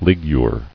[lig·ure]